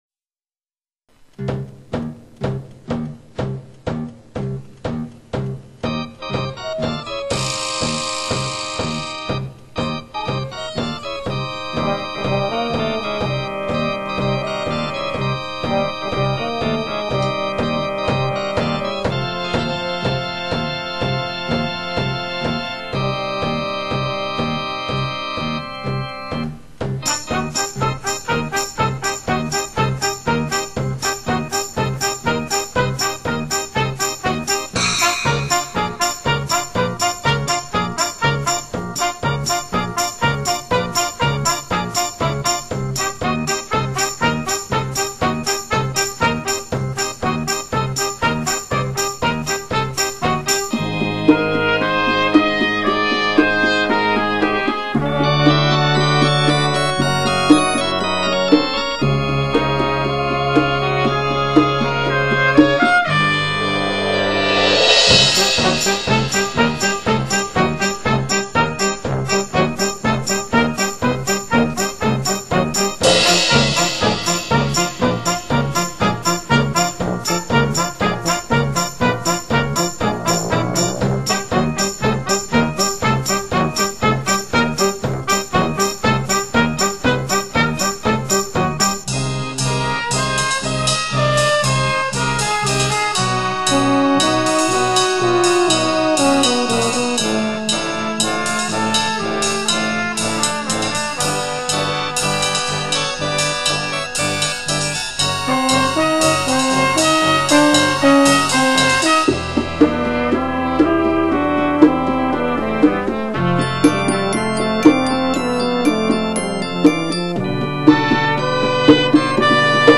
short, raucous, and fun piece
Listen to the original chamber ensemble version.